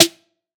SOUTHSIDE_snare_knock_on.wav